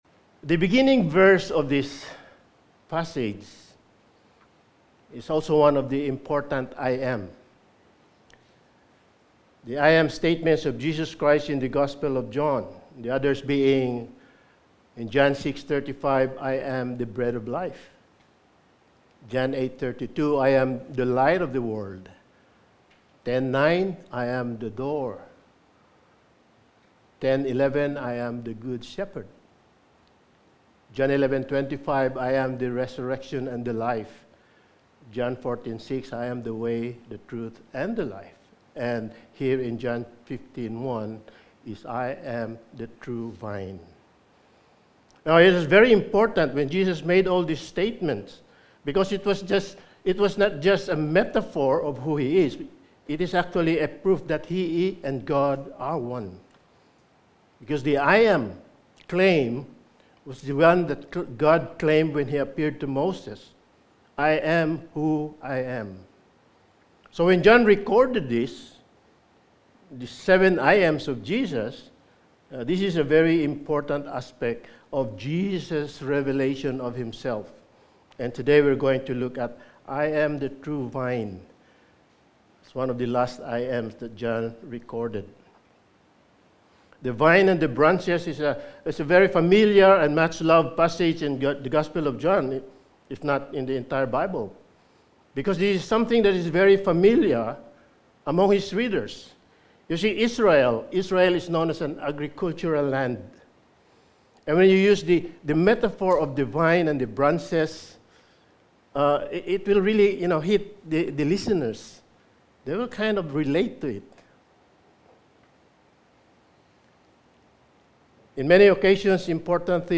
Lessons From the Upper Room Series – Sermon 8: The True Vine
Passage: John 15:1-17 Service Type: Sunday Morning